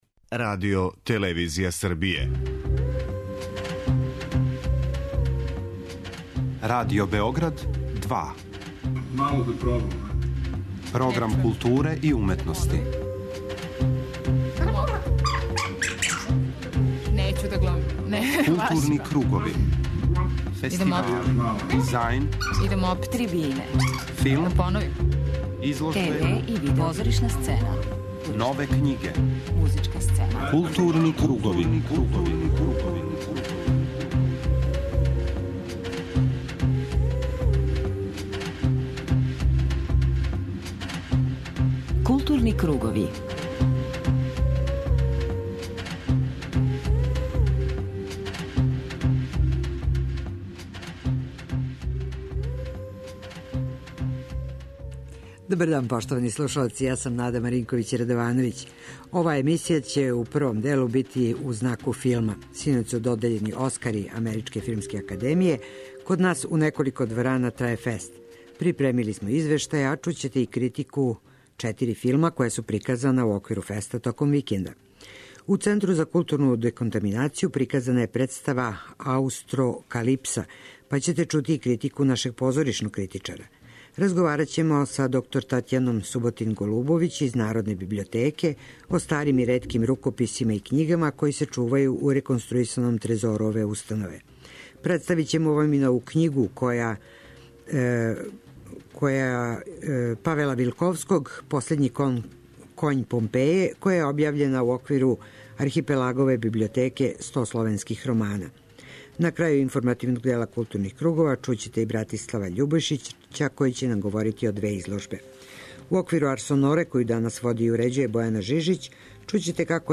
преузми : 54.69 MB Културни кругови Autor: Група аутора Централна културно-уметничка емисија Радио Београда 2.